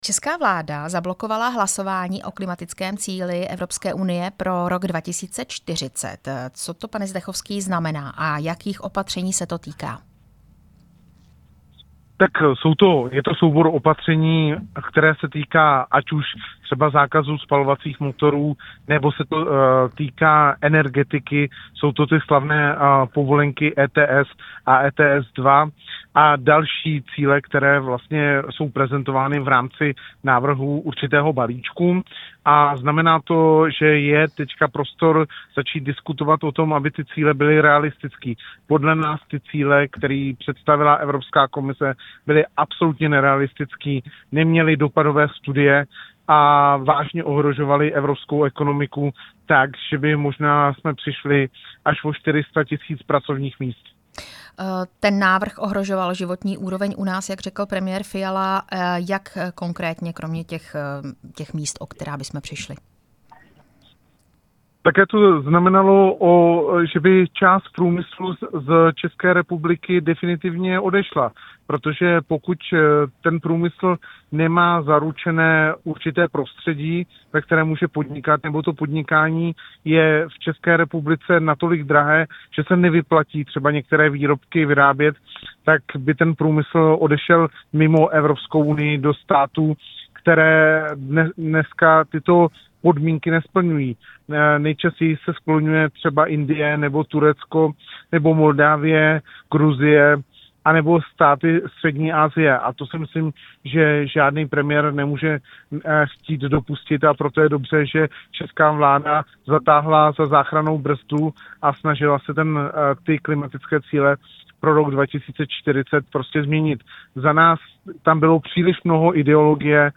Rozhovor s europoslancem Tomášem Zdechovským